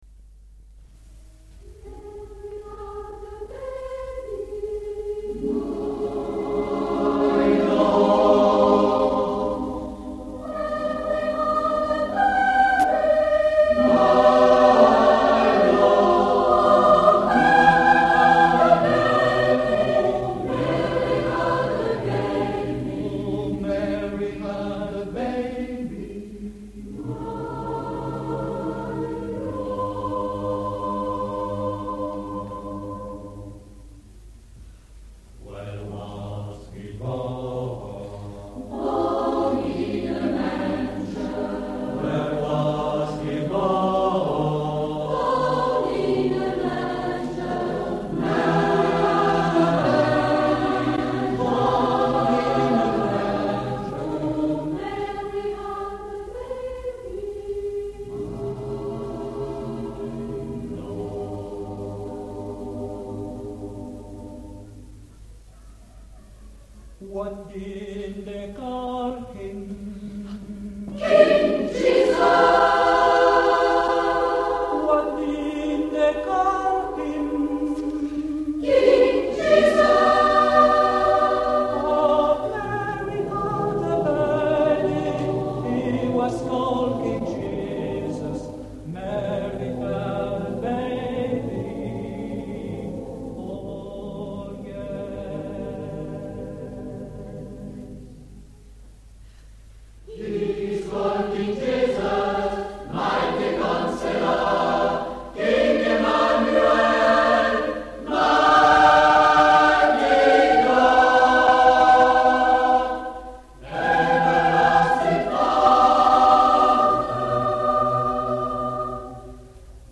Eglise Notre Dame de la Paix MACON
Extraits du concert